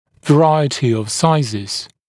[və’raɪətɪ əv ‘saɪzɪz][вэ’райэти ов ‘сайзиз]разнообразие размеров, большое количество разных размеров